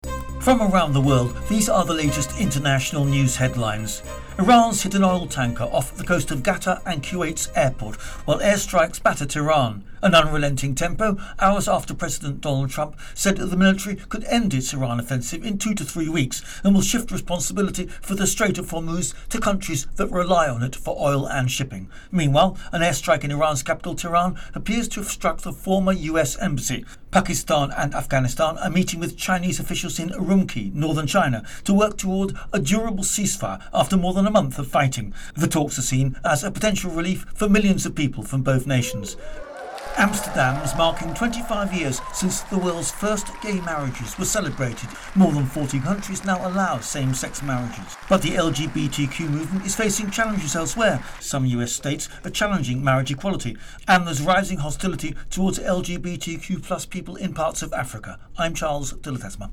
The latest AP news headlines